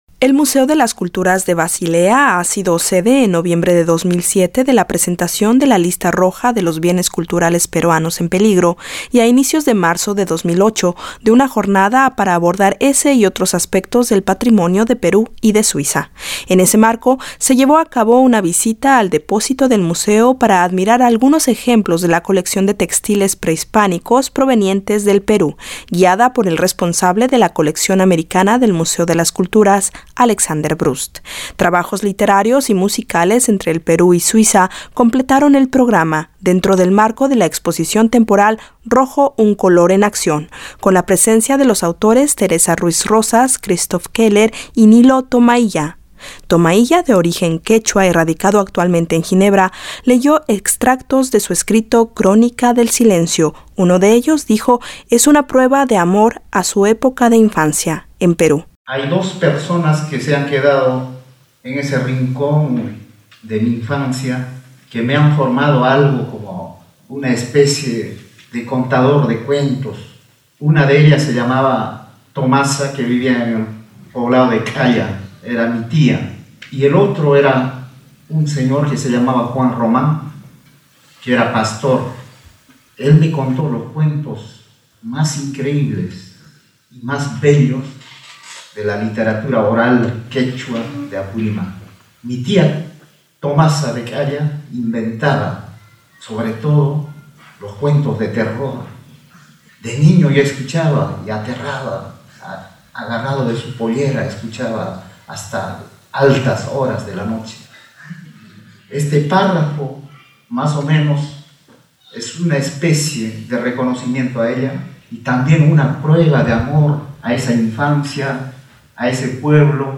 informa.